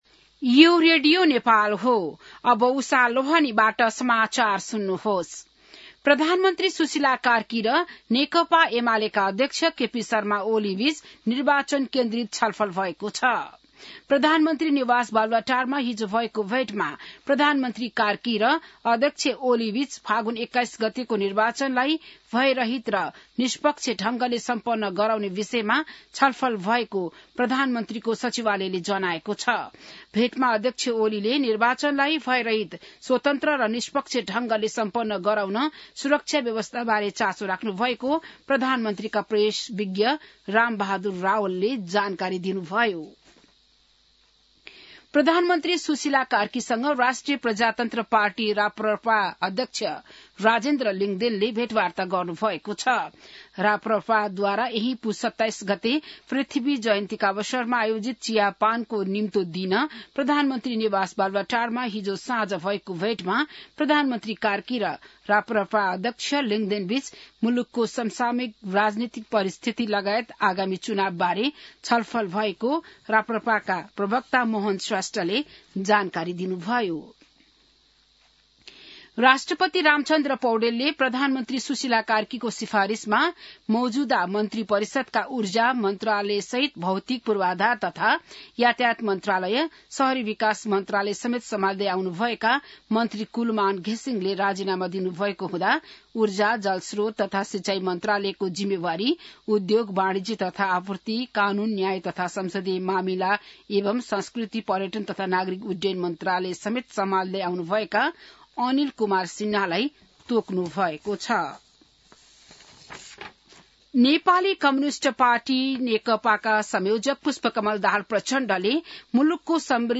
An online outlet of Nepal's national radio broadcaster
बिहान १० बजेको नेपाली समाचार : २५ पुष , २०८२